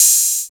47 OP HAT.wav